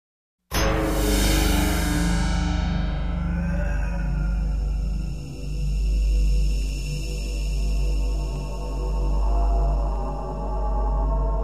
SUSPENSE
Tonos EFECTO DE SONIDO DE AMBIENTE de SUSPENSE
Suspense.mp3